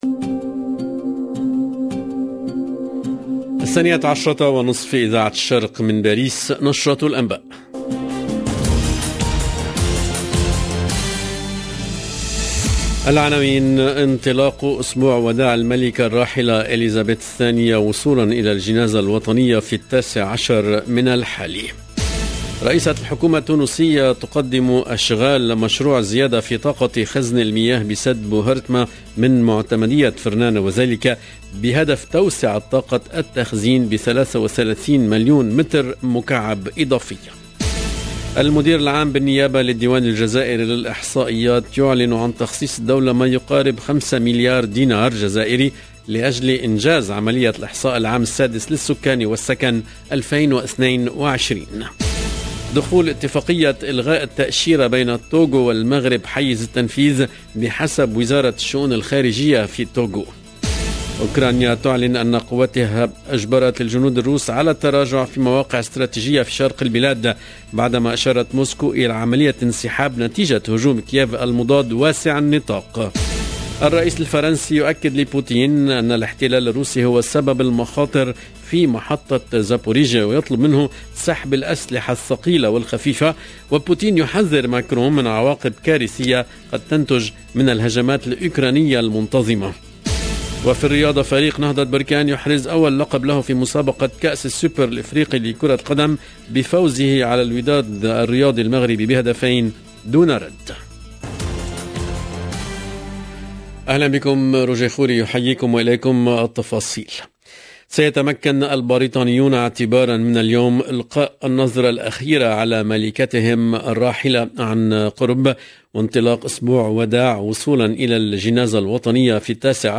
LE JOURNAL DE MIDI 30 EN LANGUE ARABE DU 12/09/22